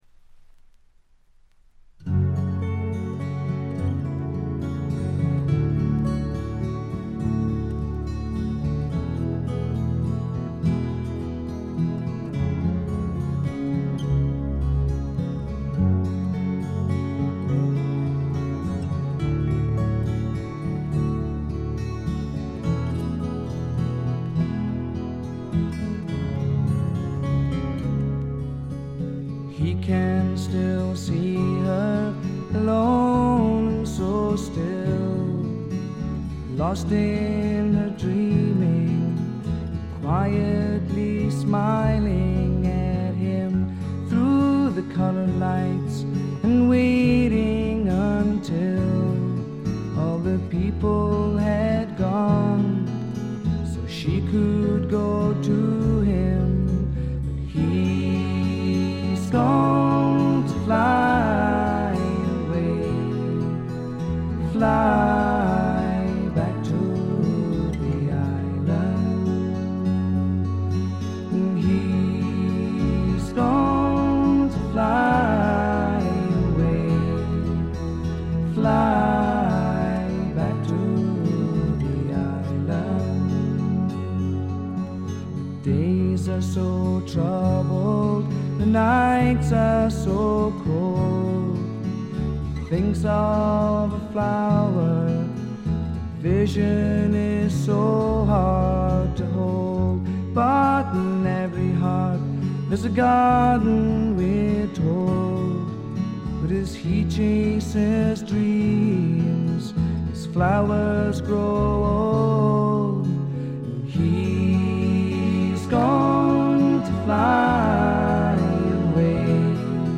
ほとんどノイズ感無し。
試聴曲は最大の魅力である哀愁味あふれる沁みる歌声に焦点を当てましたが、この人は楽器の腕前も一級品です。
試聴曲は現品からの取り込み音源です。